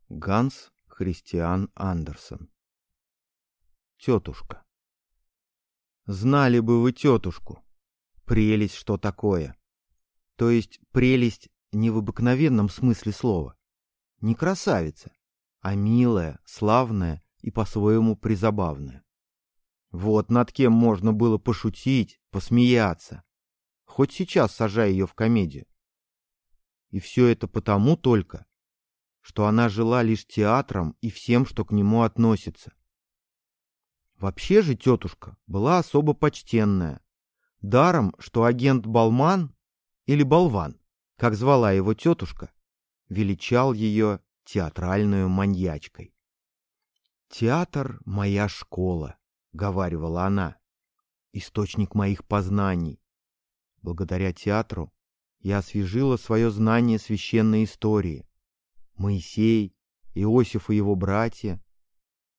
Аудиокнига Тётушка | Библиотека аудиокниг